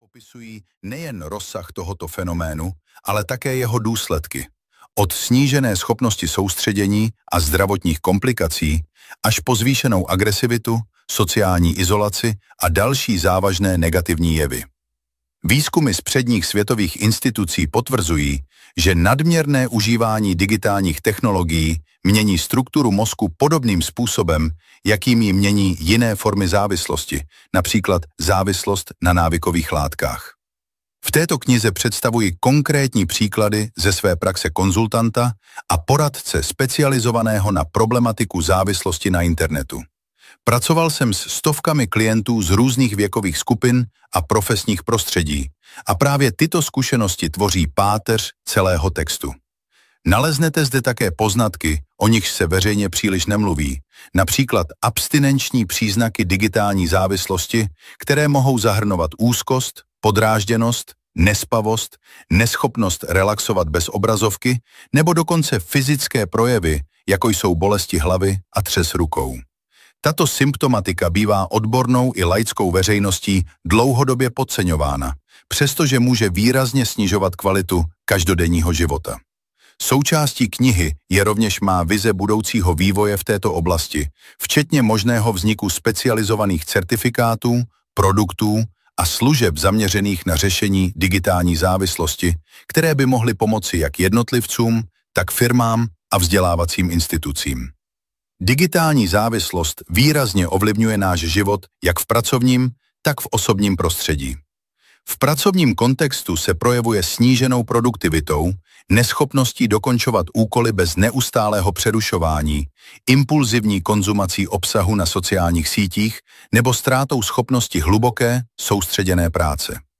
Digitální závislost audiokniha
Ukázka z knihy